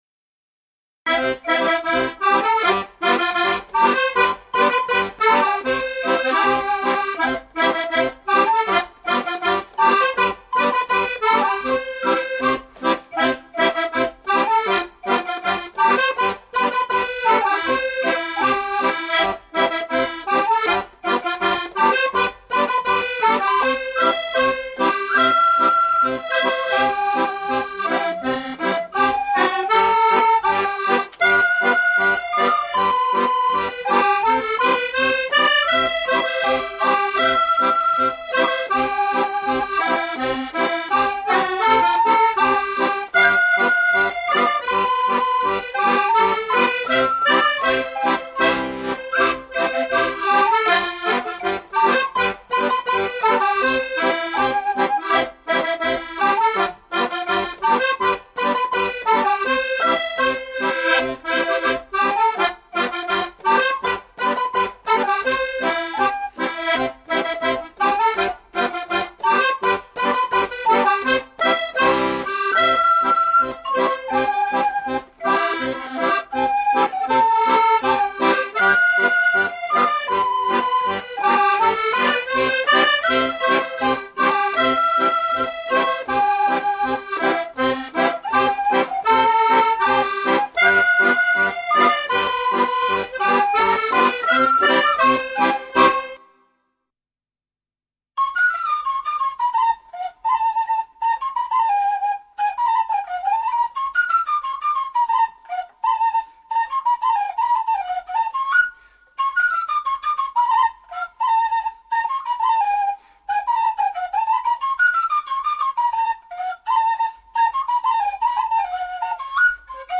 marchecortegemariage.mp3